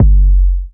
808-Kick [Sweet].wav